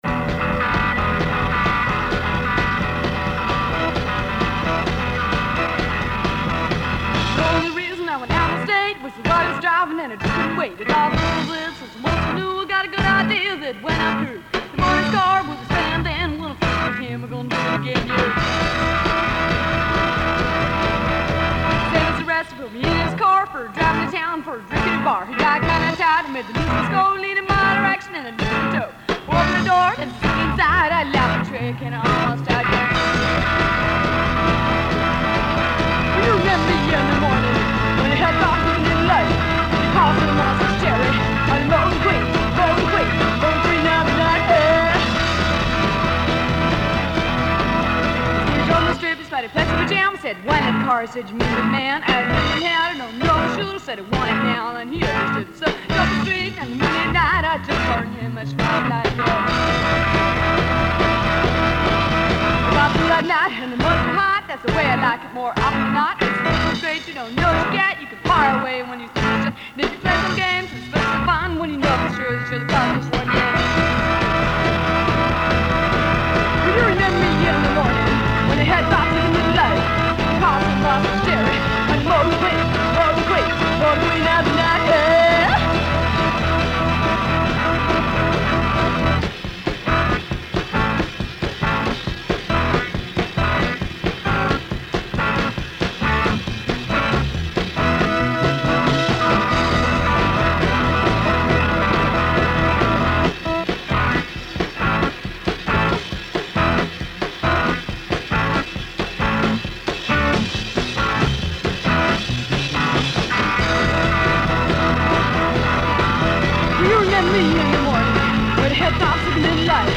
female rock pioneers